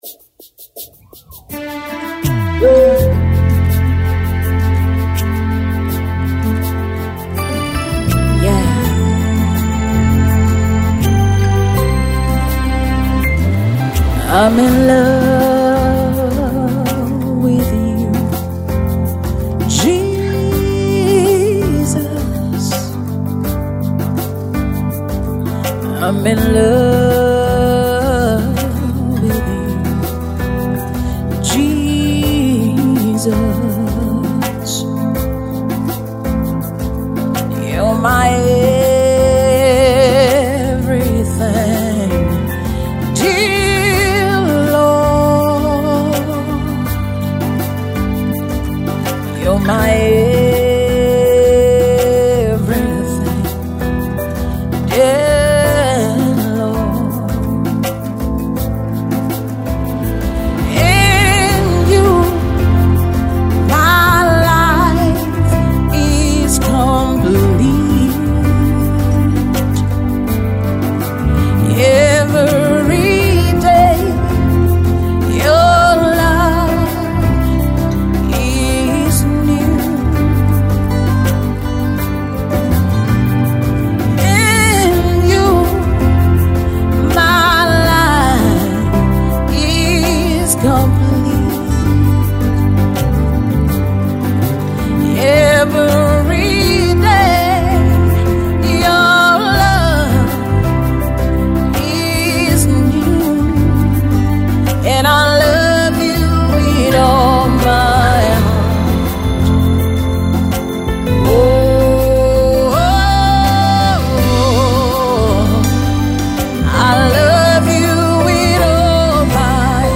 Tags:    Gospel Music,   Naija Music,   Lyrics